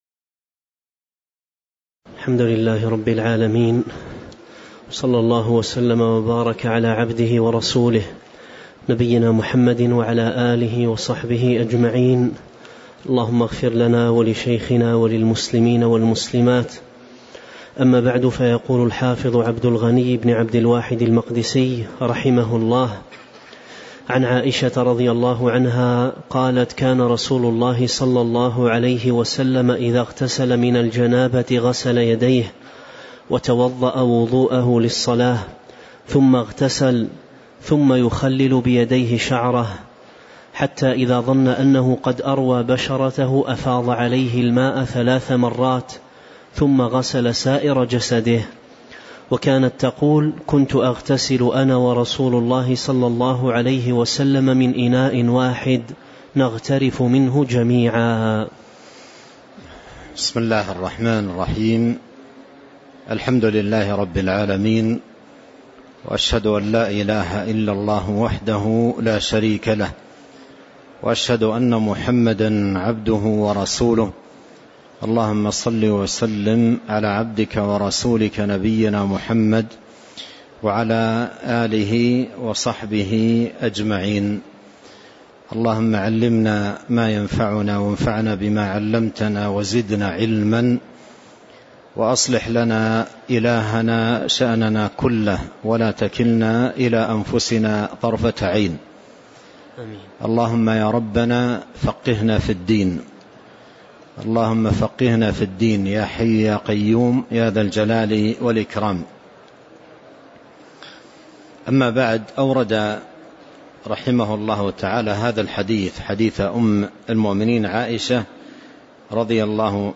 تاريخ النشر ١٥ ربيع الأول ١٤٤٤ هـ المكان: المسجد النبوي الشيخ